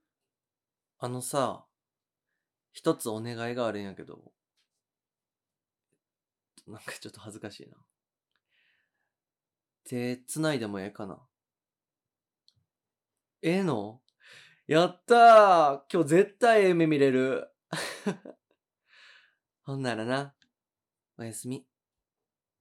タカシ おやすみボイス